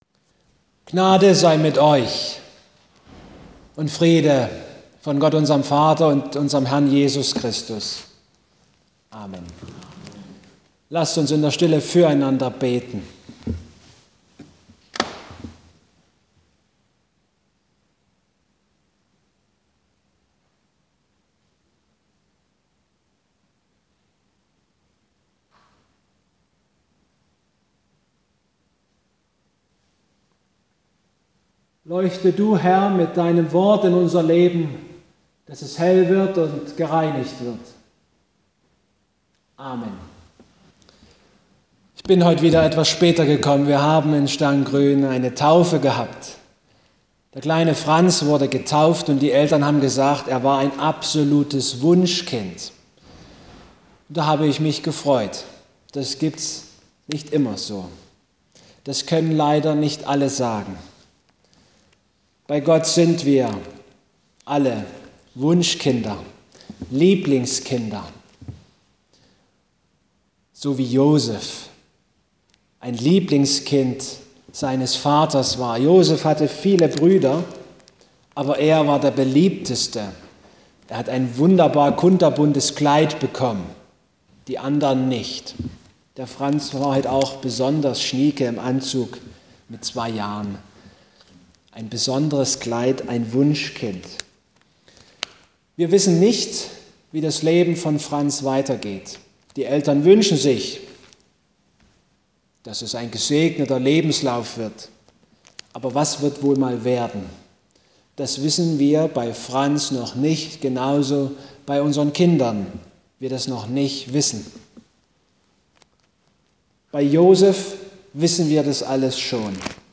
Mose 37-50 Gottesdienstart: Abendmahlsgottesdienst Die großartige Geschichte von Josef und seinen Brüder ermutigt mich, meine eigene Lebensgeschichte in einem neuen Licht zu sehen.